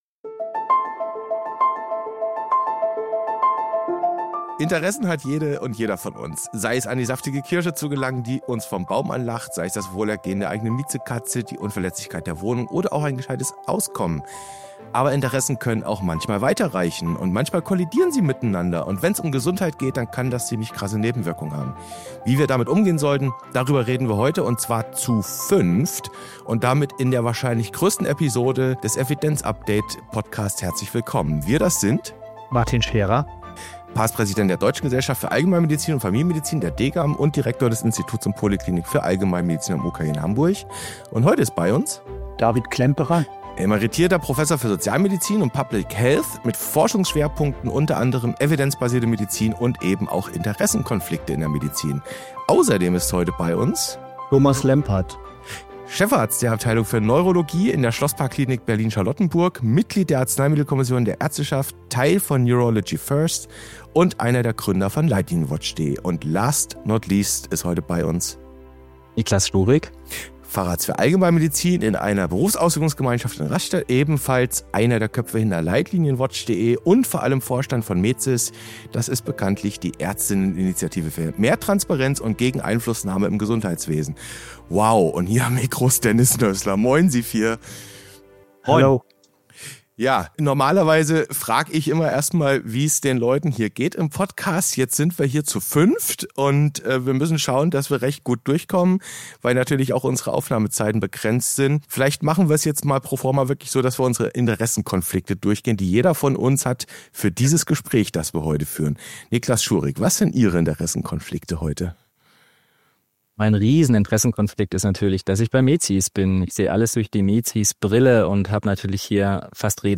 Und die zweite Folge ist diese heutige Podcast-Episode: Ein Gespräch zu fünft (!) über die strukturelle Verwundbarkeit der modernen Medizin, nämlich über Interessenkonflikte. Dieses Gespräch führt uns (Spoiler!) in den methodischen und auch in einen kulturellen Kaninchenbau.